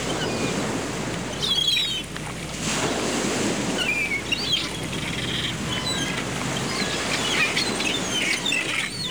seagulls.wav